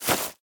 latest / assets / minecraft / sounds / block / roots / step4.ogg
step4.ogg